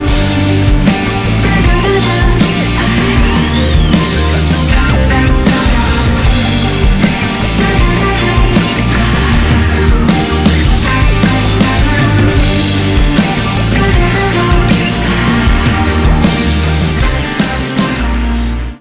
• Качество: 192, Stereo
Не спеша и мелодично женский голос поет простую мелодию